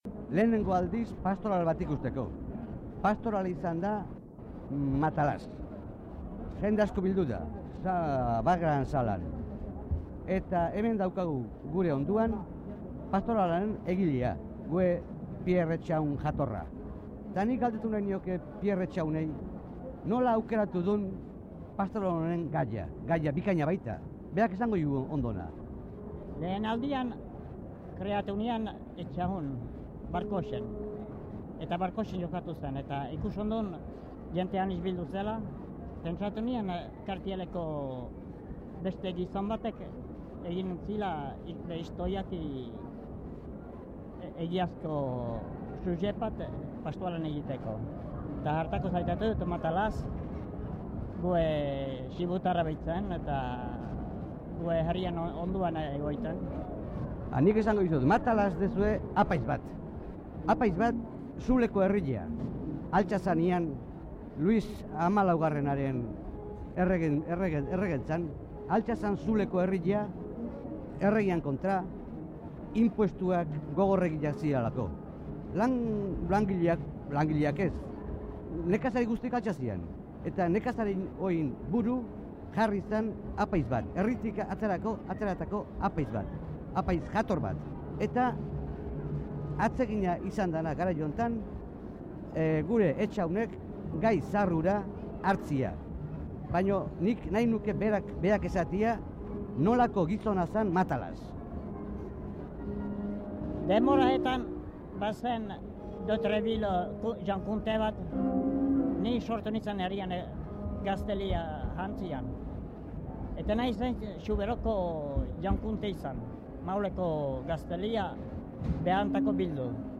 Pierre Etxahun Irurikoa elkarrizketa 1964
Matalaz pastorala Parisen eskaini zen 1964an eta horren harira Radio Paris irratian elkarrizketatu zuten Pierre Etxahun Irurikoa.